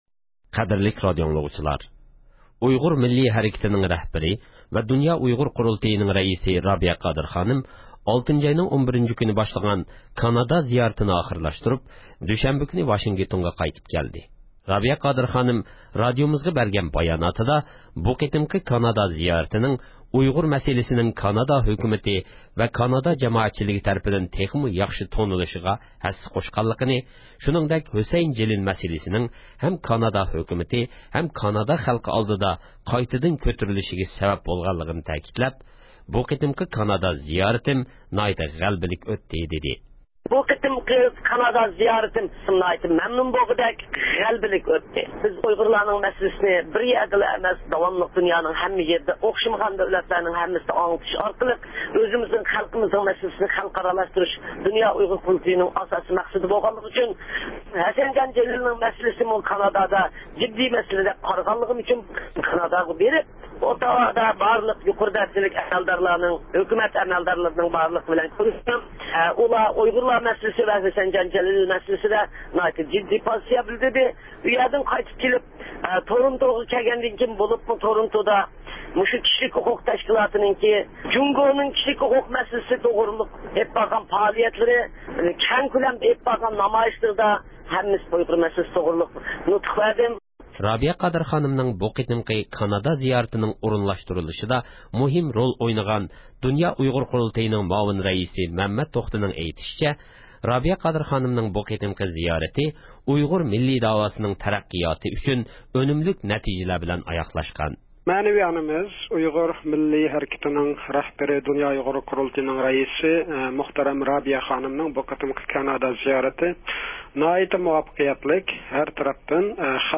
خەۋىرى